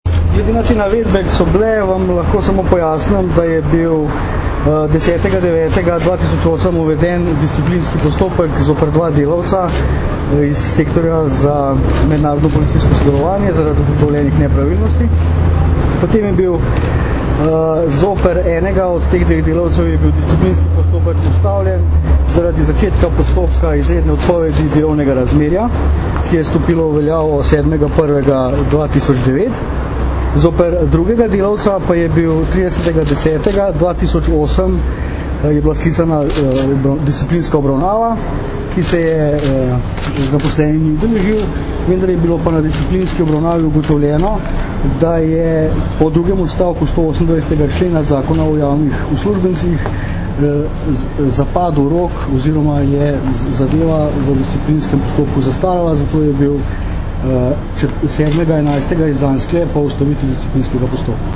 Zvočni posnetek izjave Pavla Jamnika (mp3)